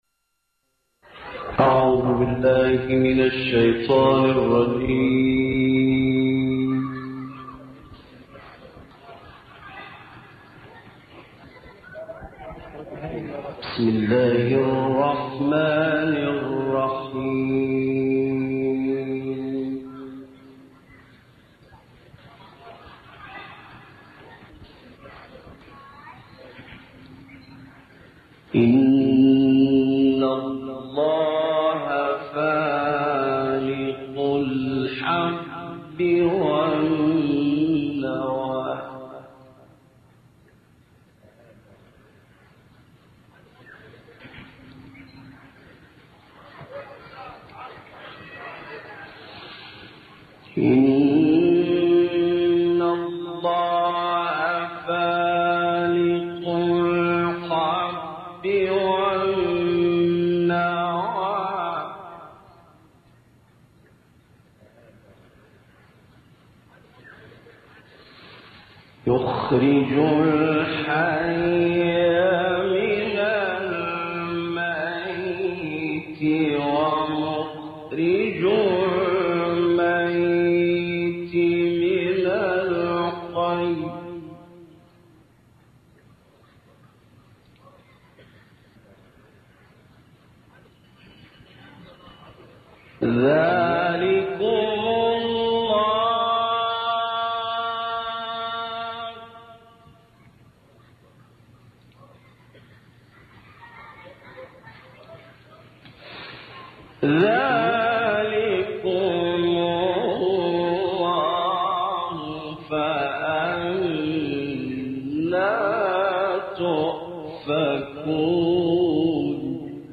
تلاوت آیات ۱۰۴- ۹۵ سوره انعام با صدای استاد اللیثی+ دانلود
گروه فعالیت‌های قرآنی: قطعه‌ای دلنشین از تلاوت محمد اللیثی از آیات ۱۰۴-۹۵ سوره انعام ارائه می‌شود.